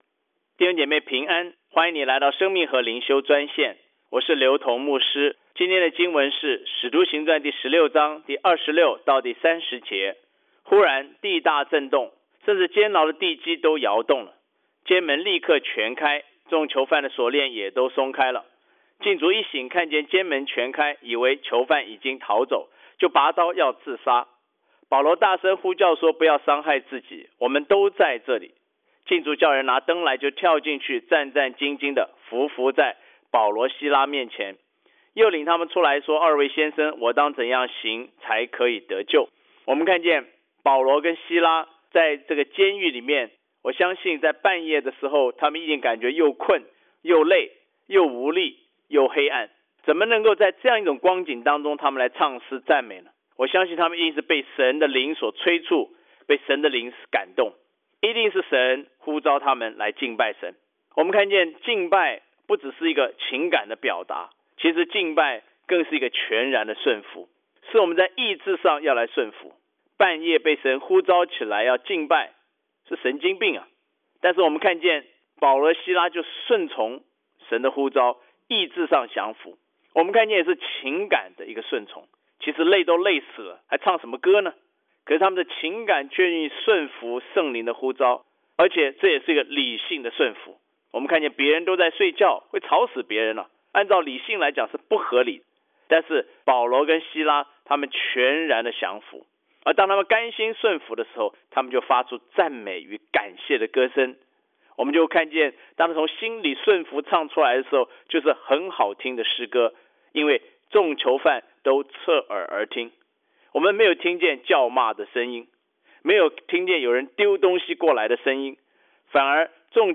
藉着每天五分钟电话分享，以生活化的口吻带领信徒逐章逐节读经